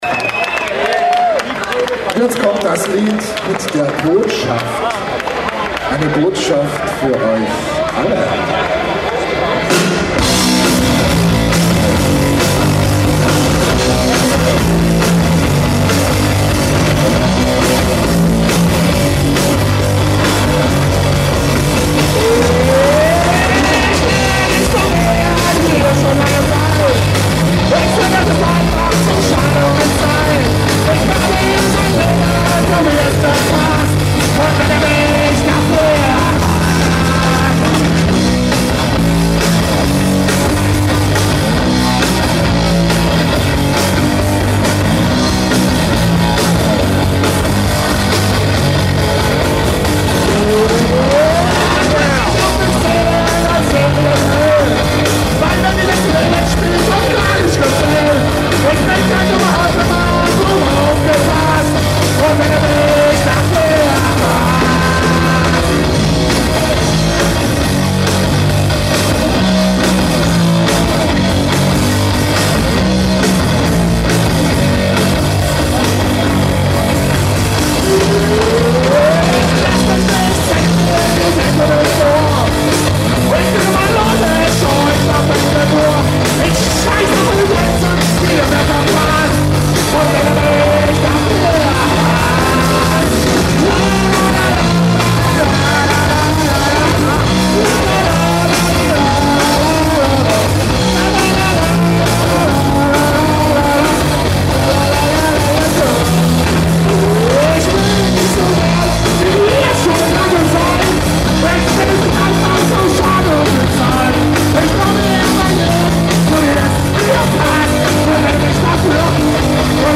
nein nicht das ganze konzert, ihr sollt sie ja kaufen!